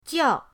jiao4.mp3